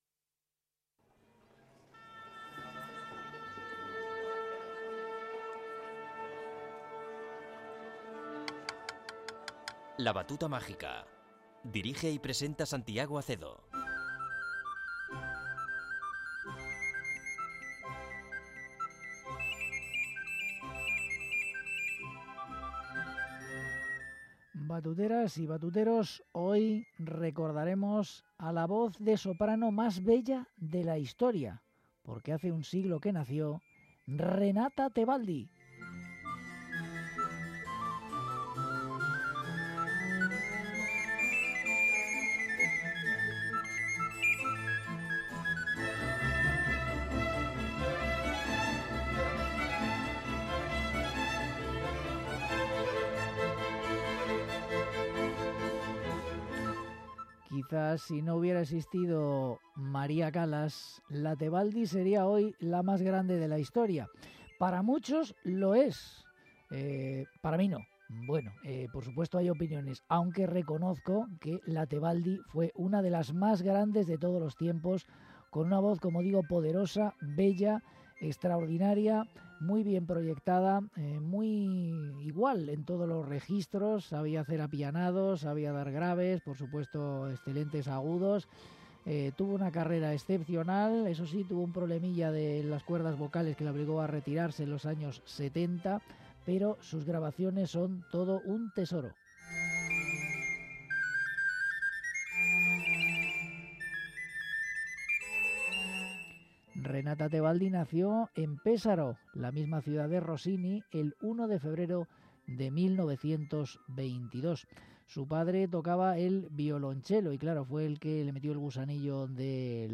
soprano
arias